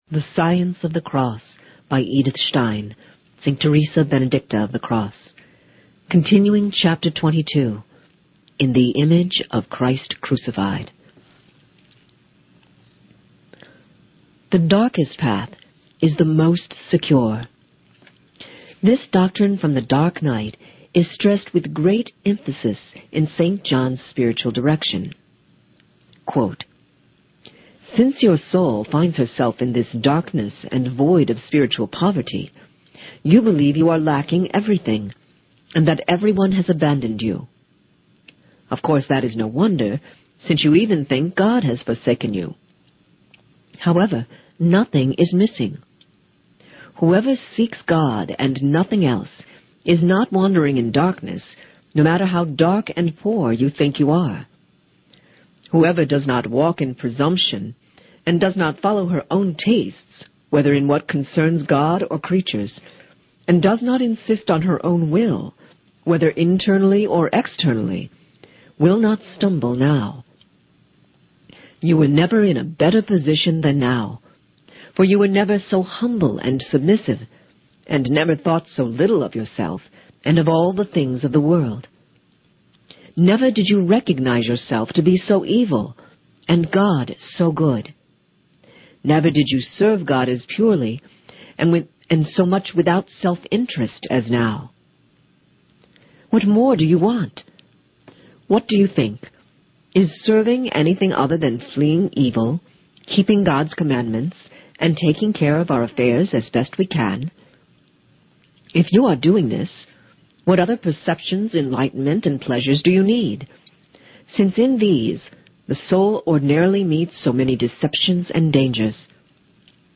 Insights Readings from timeless Christian literature and writings of the saints and doctors of the Church.